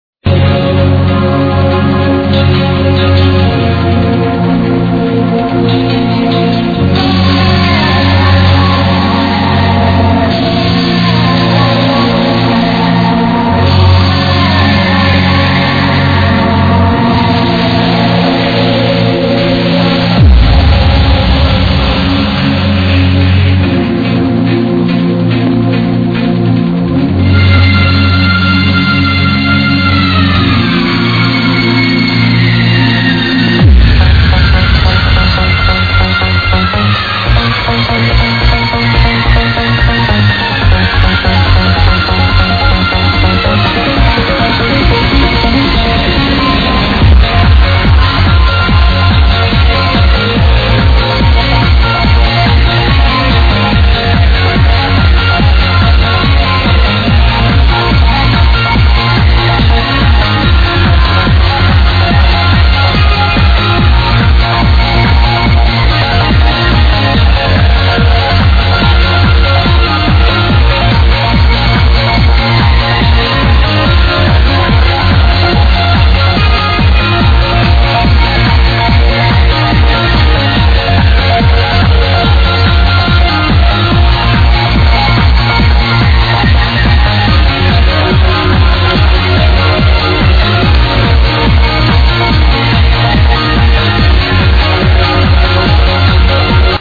Please help id this uplifting tune from 2001.